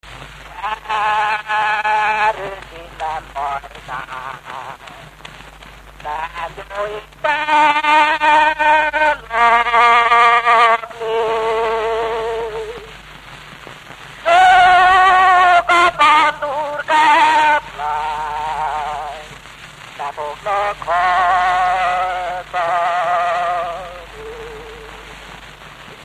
Dunántúl - Tolna vm. - Gerjen
ének
Gyűjtő: Lajtha László
Stílus: 9. Emelkedő nagyambitusú dallamok